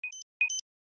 TW_Battery_caution.ogg